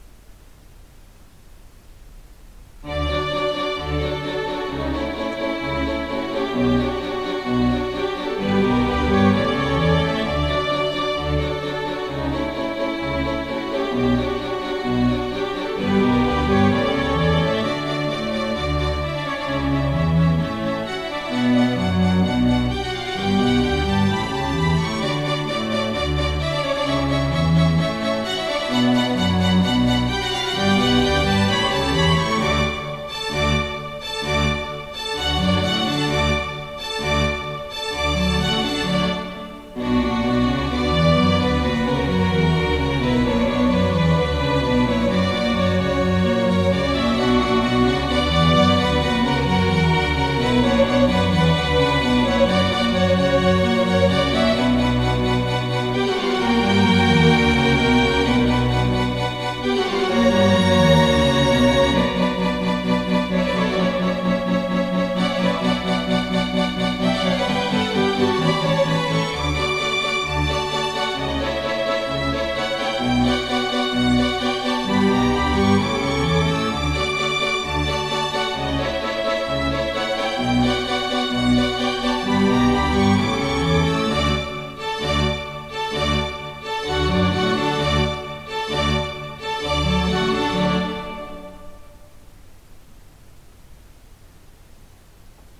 マイクロホンの位置は、フロントスピーカーから1.8ｍ離した高さ1mの場所。